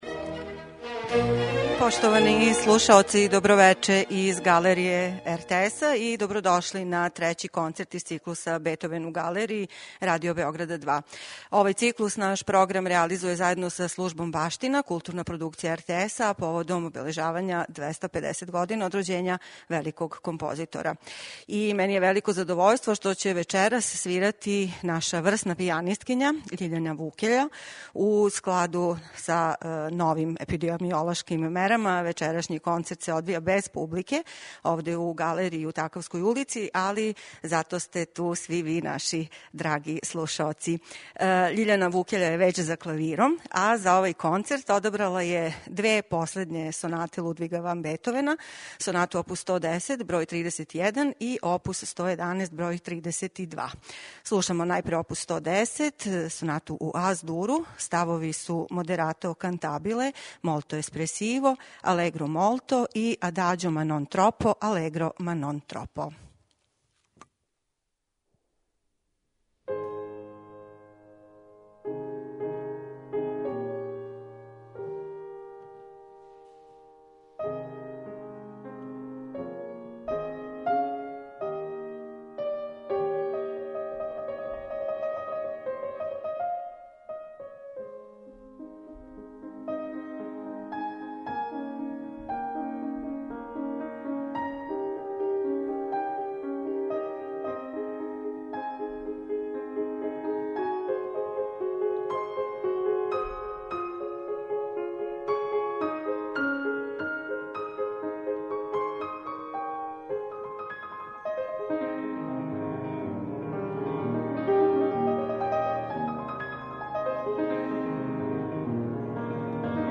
клавир